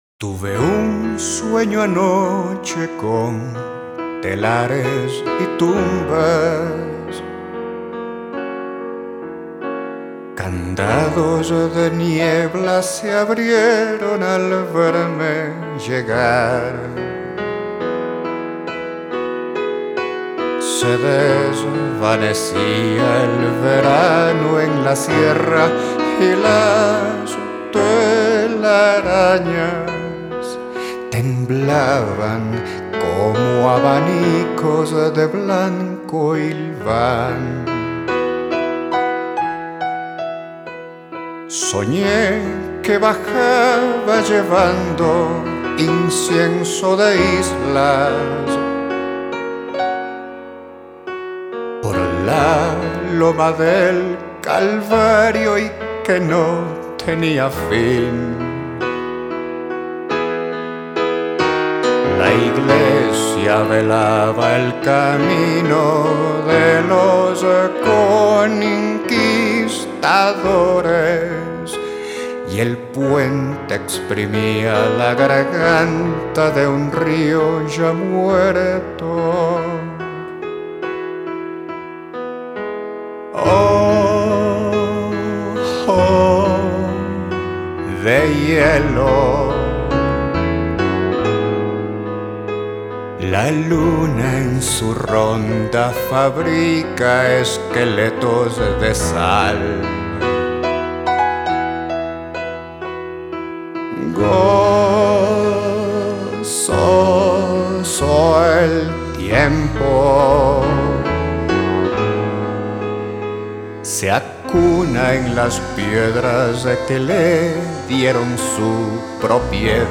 bajo, piano eléctrico.
guitarra.
violín 1.
cello.
batería.
congas, maracas, caxixis, campana.